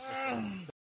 Play, download and share X-argg-2 original sound button!!!!
aaaahh-online-audio-converter.mp3